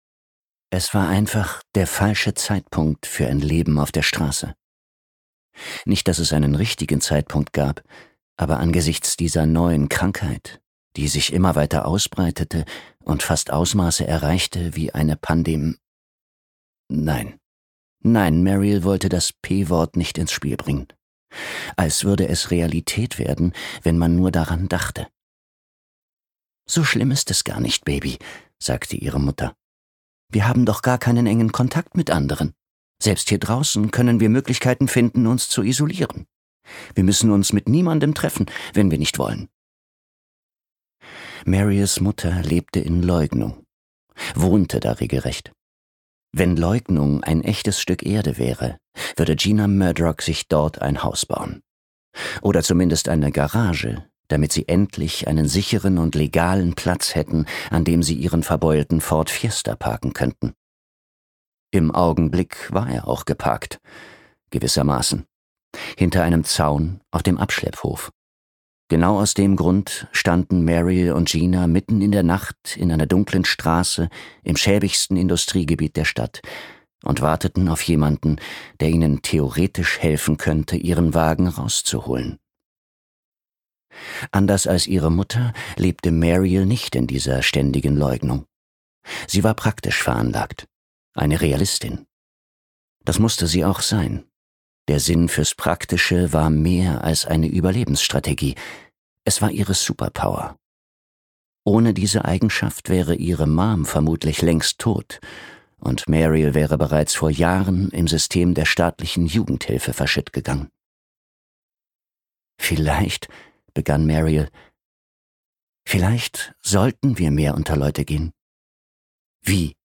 All Better Now - Neal Shusterman | argon hörbuch
ist ein Meister des ruhigen Erzähltons, dem man stundenlang lauschen möchte.
Gekürzt Autorisierte, d.h. von Autor:innen und / oder Verlagen freigegebene, bearbeitete Fassung.